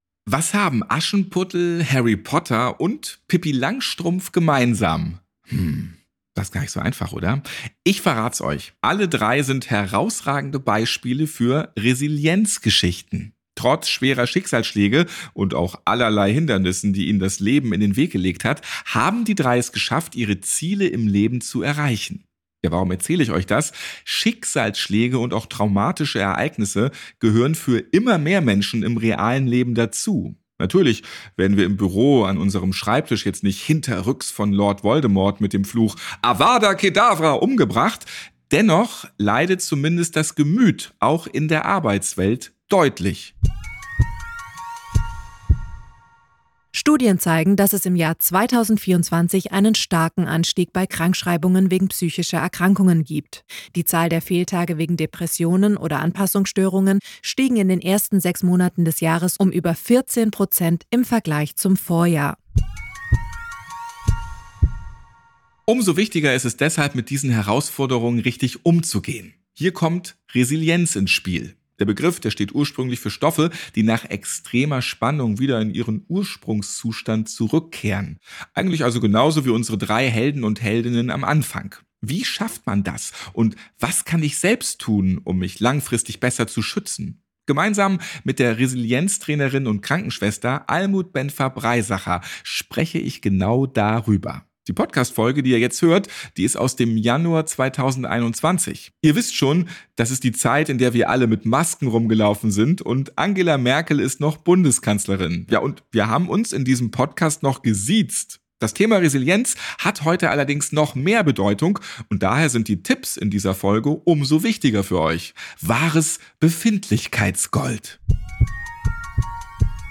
Im Fokus steht das Arbeitsfeld Pflege. Zu Wort kommen Fachleute, Arbeitgeberinnen und Arbeitgeber, Führungskräfte und Beschäftigte.